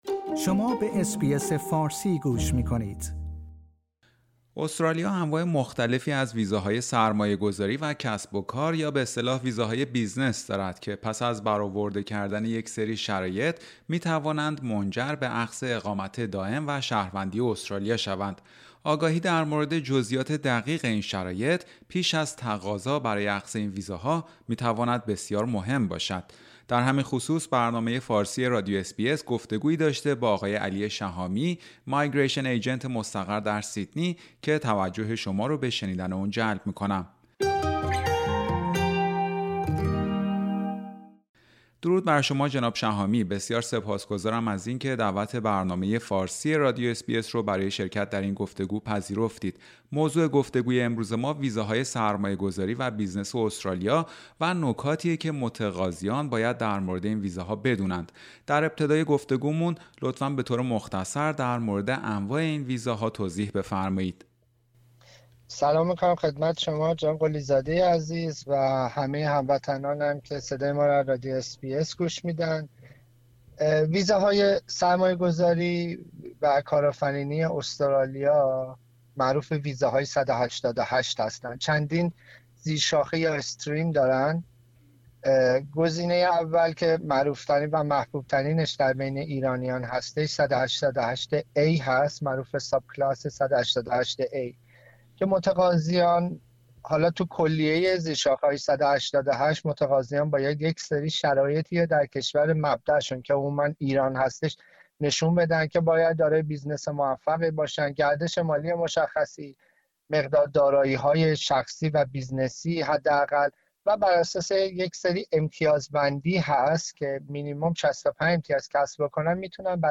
گفتگویی